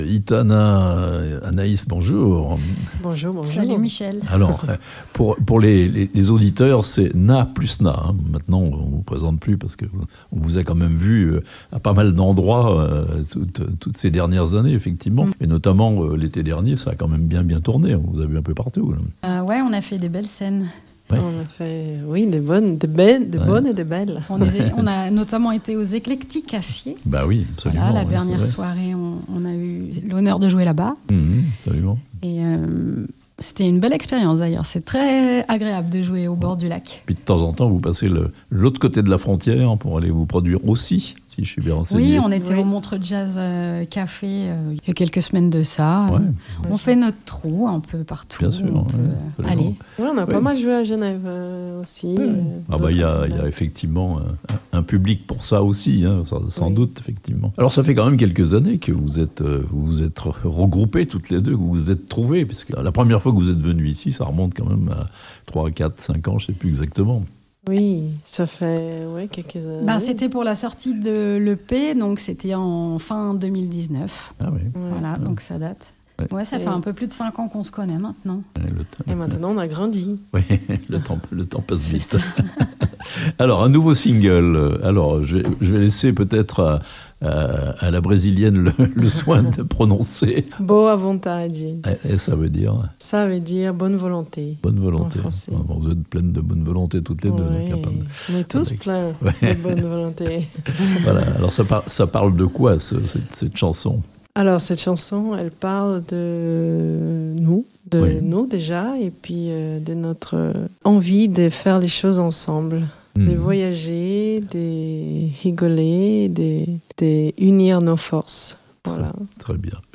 Un nouveau titre enregistré par les chanteuses du groupe NA+NA (interviews)